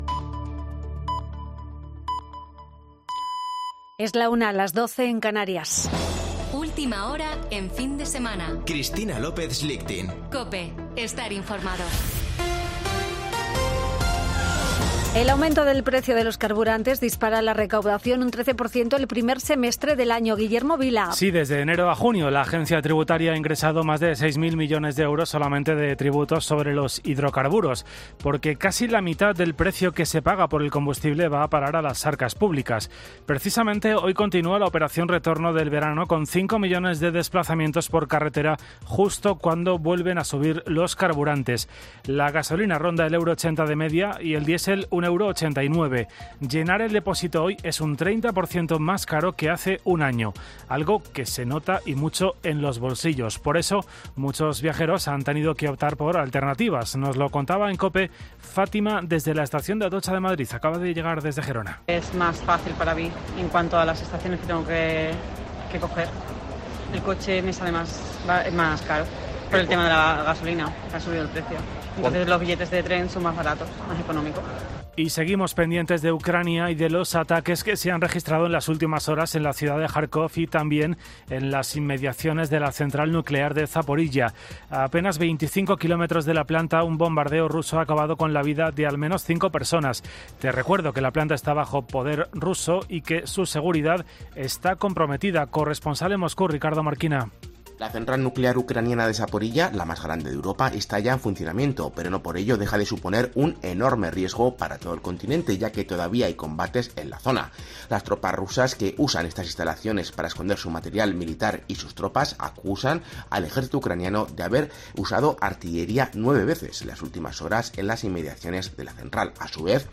Boletín de noticias de COPE del 27 de agosto de 2022 a las 13.00 horas